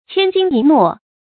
千金一諾 注音： ㄑㄧㄢ ㄐㄧㄣ ㄧ ㄋㄨㄛˋ 讀音讀法： 意思解釋： 諾：許諾。一個諾言價值千金。指守信用，不輕易許諾。